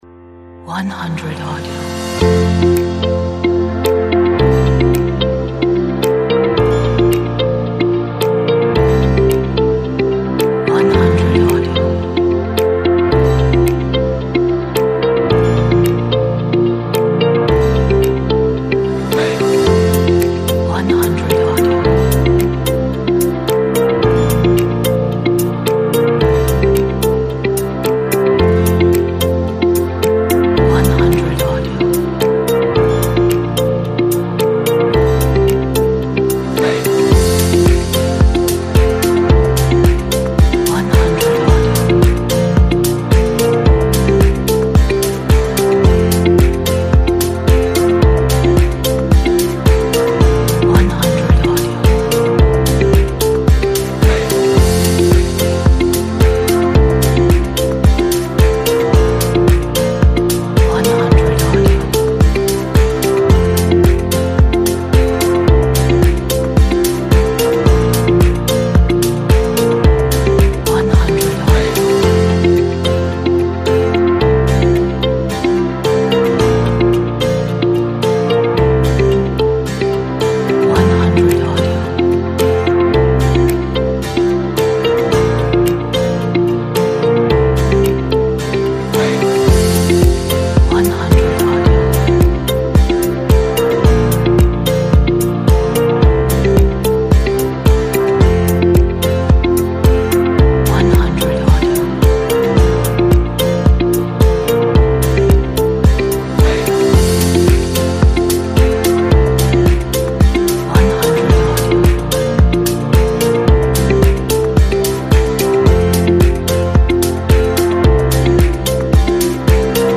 Very inspiring!
Motivated motivational upbeat power energy.